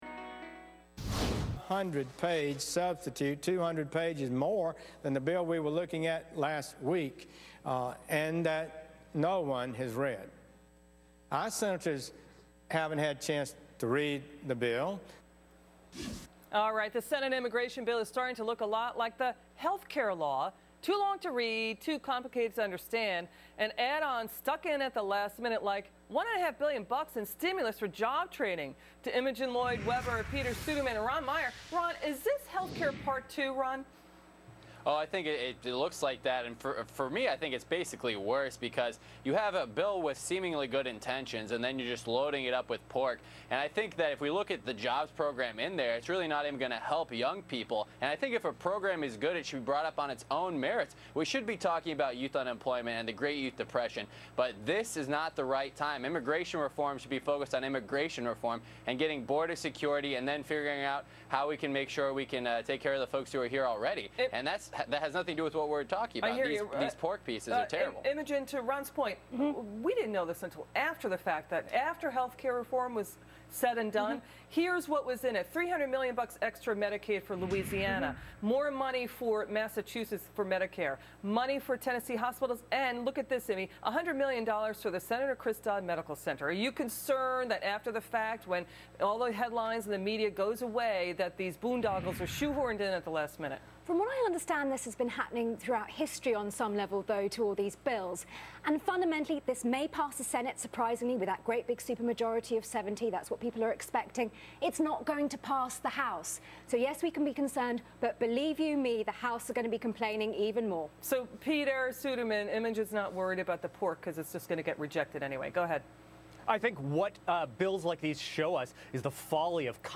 on Fox Business News' Cavuto Show